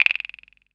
095 - SynPerc.wav